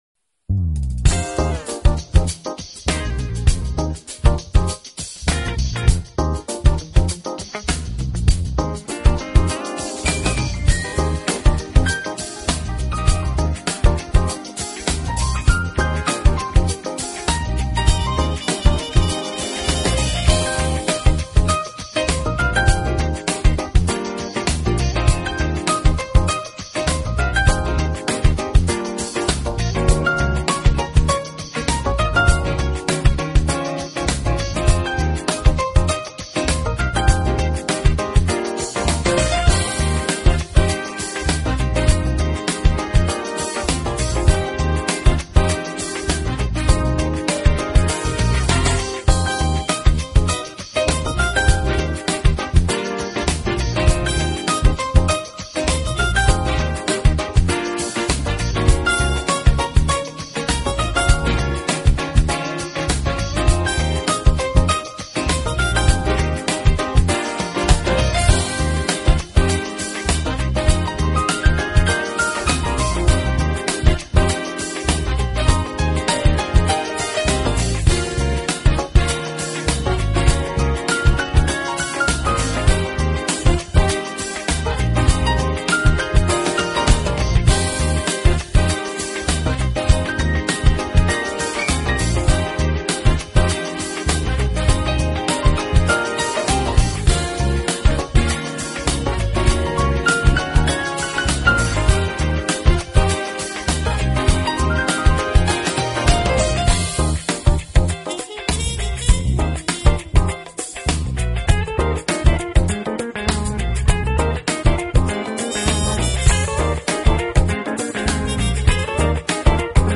Genre: Jazz / Smooth Jazz